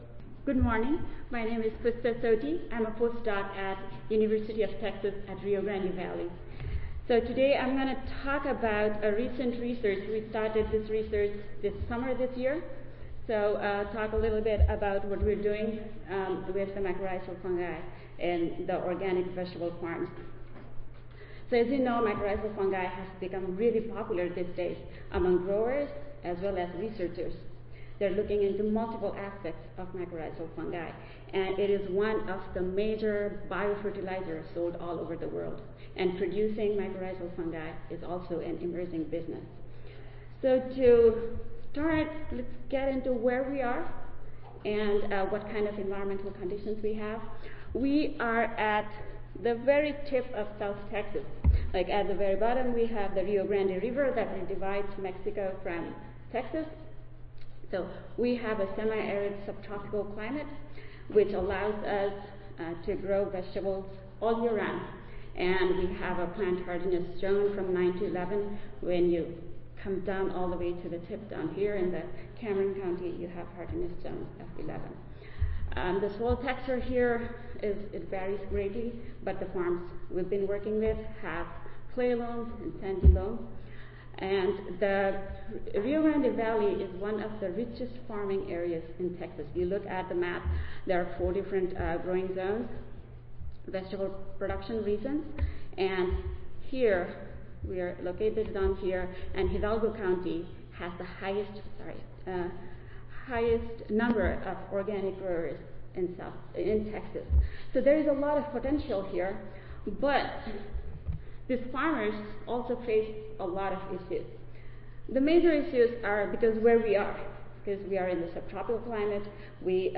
See more from this Division: Special Sessions See more from this Session: Special Session Symposium--Organic Agriculture Soil Health Research